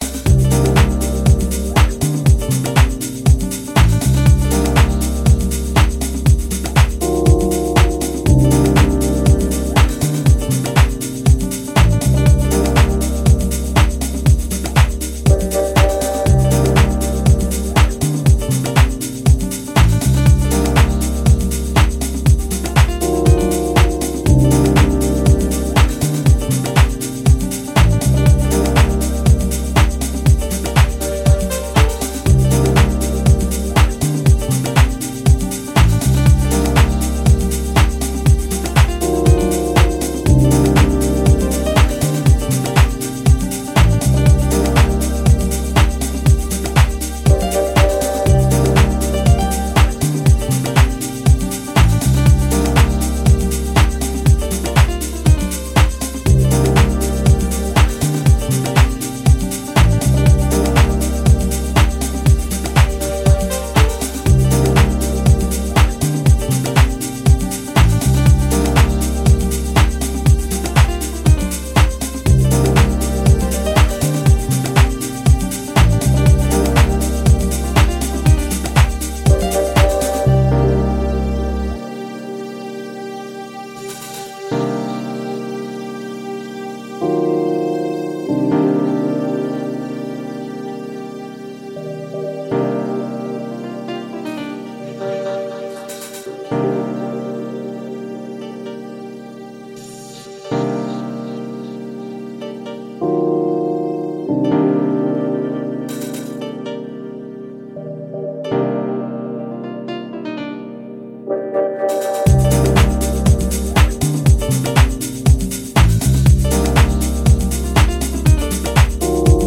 deep sounds and raw waves, perfect to floor
vocalist cut